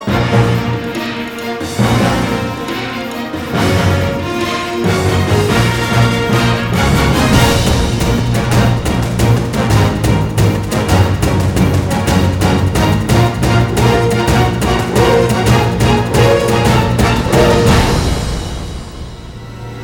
Catégorie Électronique